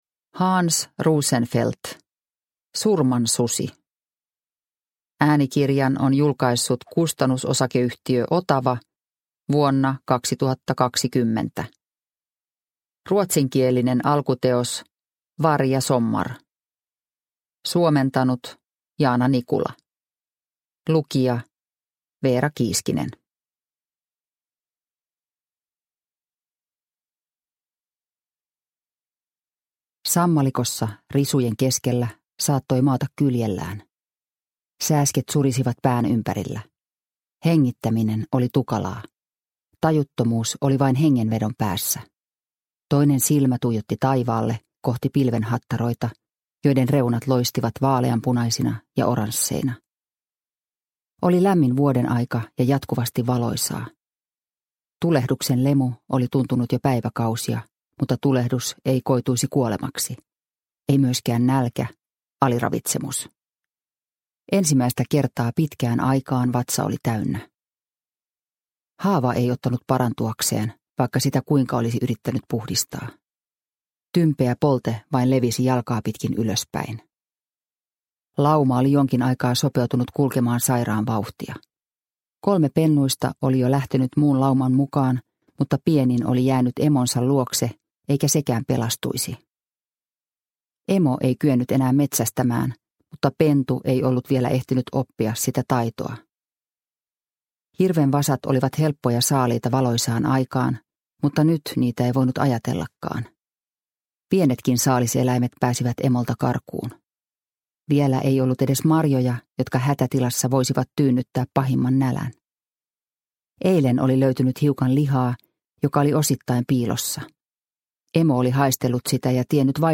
Surman susi – Ljudbok – Laddas ner